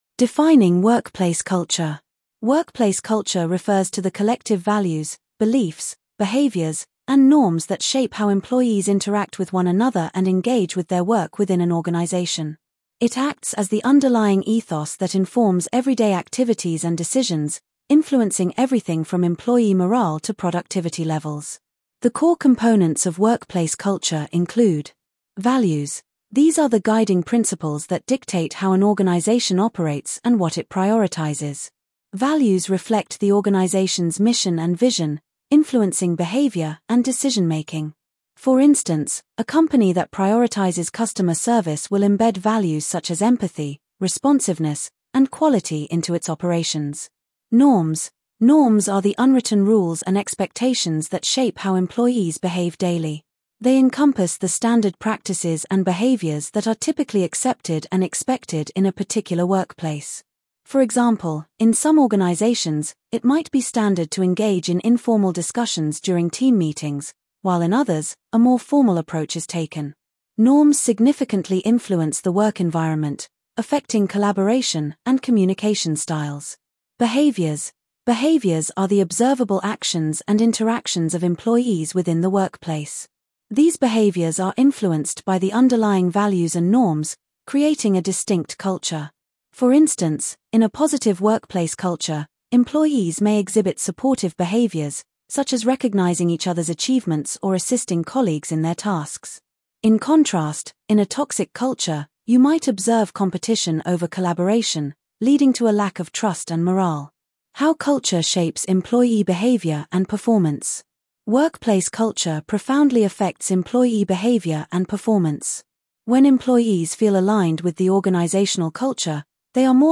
Audio lesson